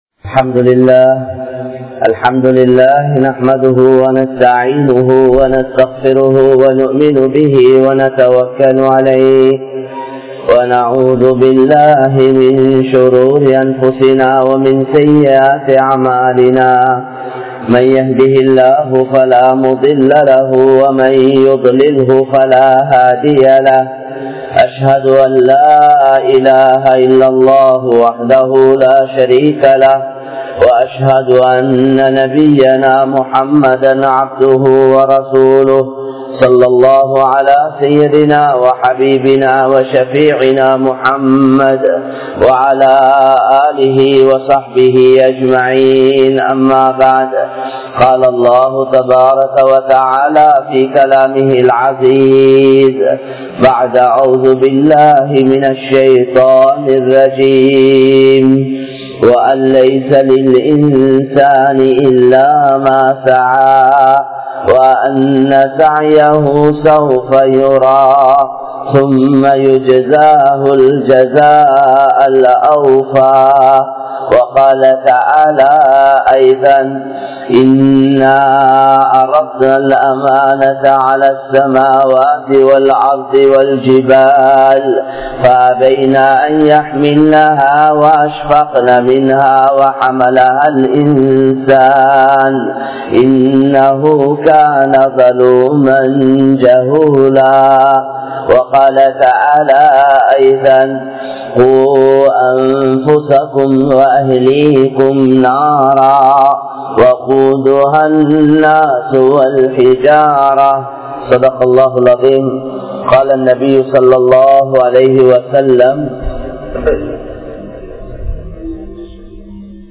Anniya Pennai Paarpathan Vilaivu Zina(அந்நிய பெண்னைப் பார்ப்பதன் விளைவு விபச்சாரம்) | Audio Bayans | All Ceylon Muslim Youth Community | Addalaichenai
Majmaulkareeb Jumuah Masjith